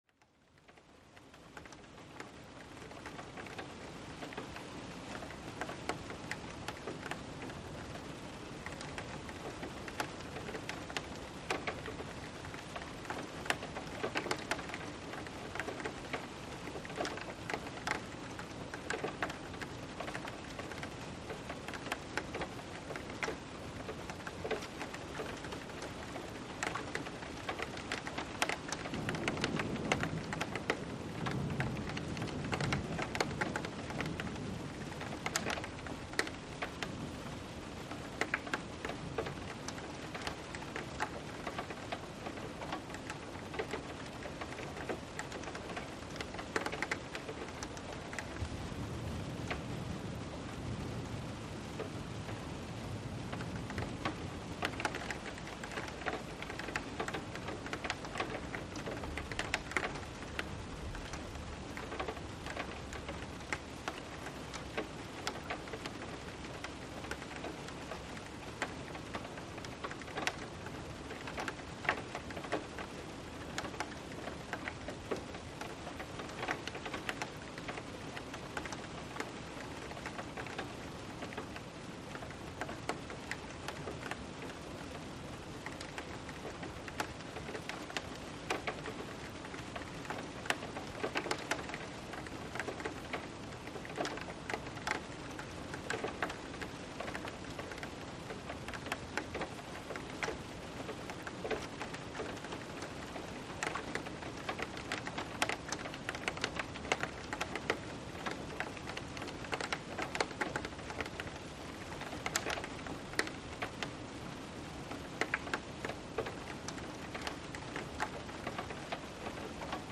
The rain patters rhythmically, and from time to time, a distant rumble of thunder can be heard.
Saga Sounds is a series of pleasant, varied soundscapes, which you can listen to when winding down, going to sleep or focusing at work. A specially designed sound universe creates a calming atmosphere, also known as "ambience", which you can enter when and where you want.